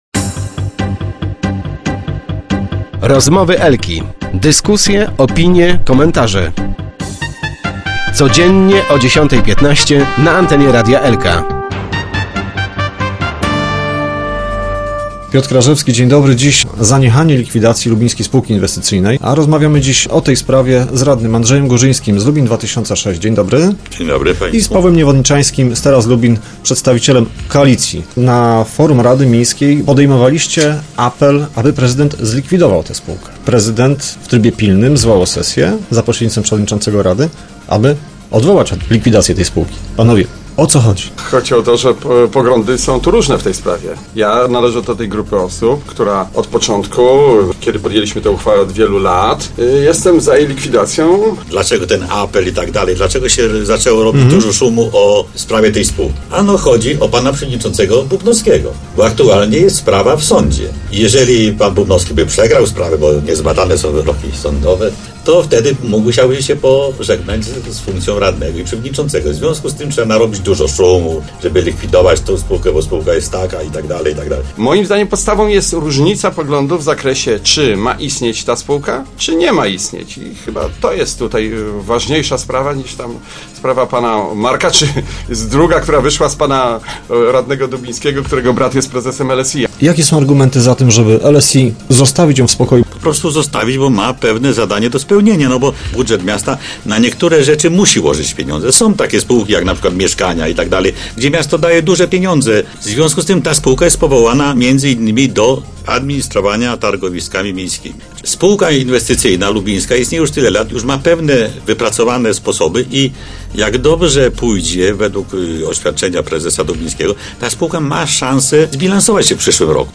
rozmawiał o tym w Rozmowach Elki z radnymi Pawłem Niewodniczańskim /na zdj z lewej/ , przedstawicielem Teraz Lubin i Andrzejem Górzyńskim z Lubin 2006.